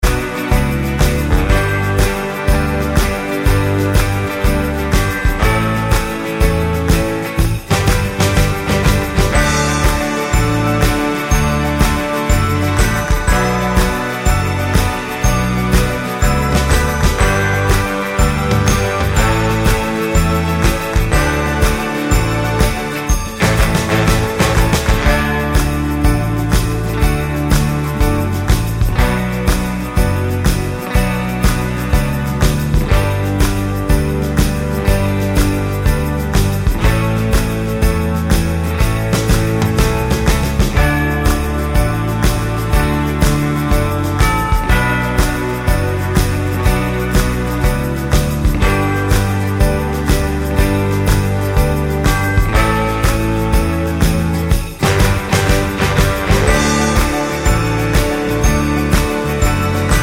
no Backing Vocals Christmas 4:07 Buy £1.50